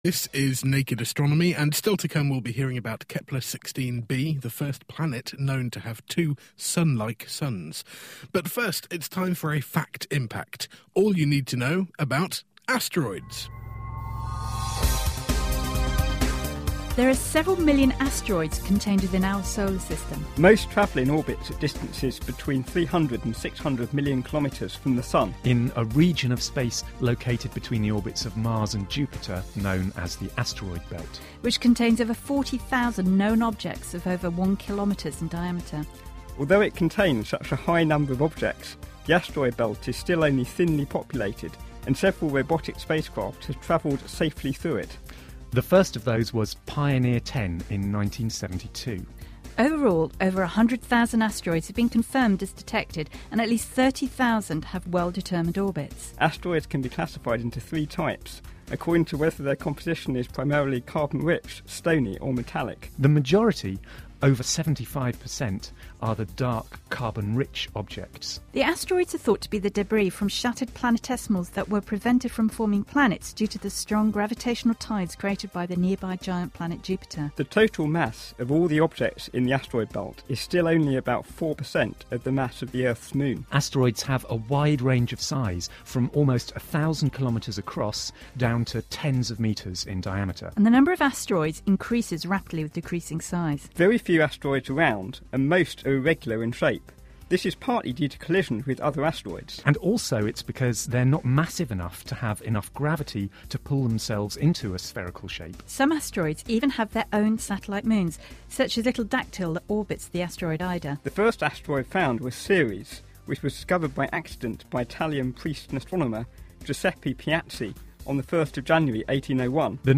Fact Impact: A high-speed run-down of facts about Asteroids